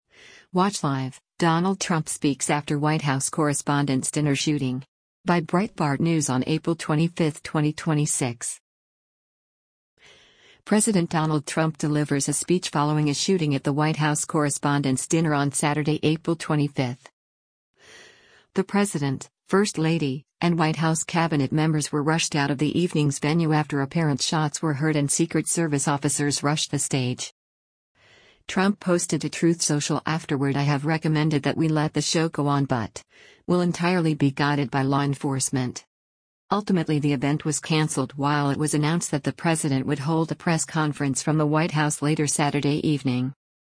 President Donald Trump delivers a speech following a shooting at the White House Correspondents’ Dinner on Saturday, April 25.